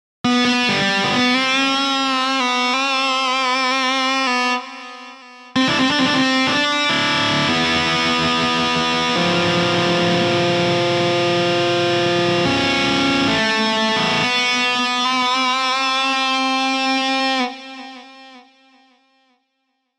Der Sound aus dem Tool direkt ist wie gesagt total trocken, eben wie ein DI Signal. Ich habe jetzt einfach mal wild in die Tasten gehauen und es ist sicherlich nicht das beste Keyboardspiel aber mit den erstbesten Presets aus Guitar Rig 5 klingt das nicht verkehrt. Klingt für meinen Geschmack schon besser als die Soundbeispiele vom Hersteller und das nach 5m rumbasteln und für 17$, passt.